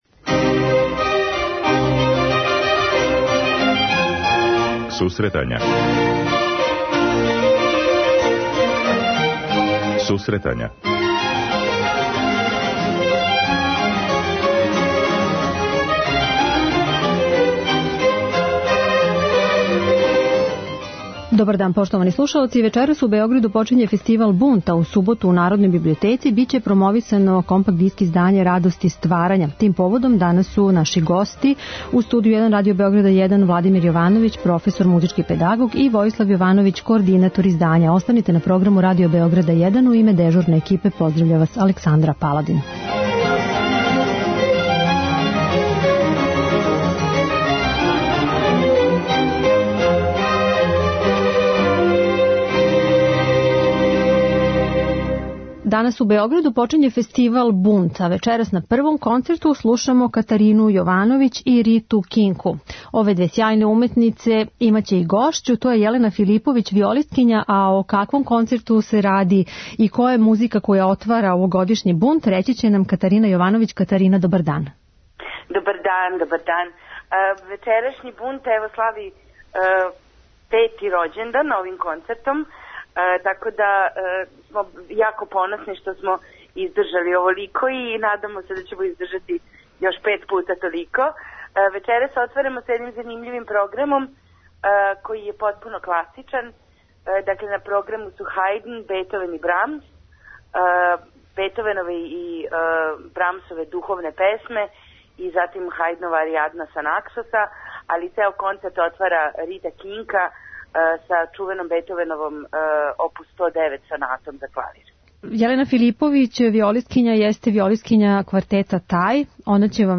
преузми : 10.75 MB Сусретања Autor: Музичка редакција Емисија за оне који воле уметничку музику.